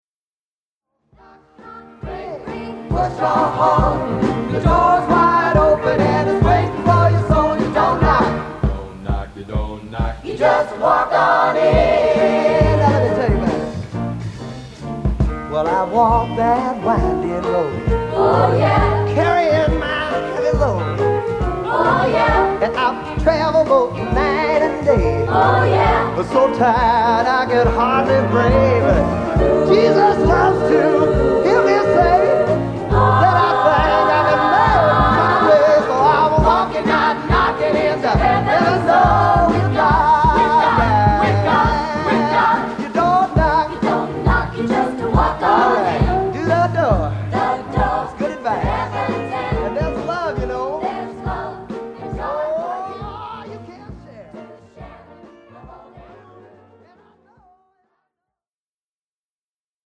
These are all live performances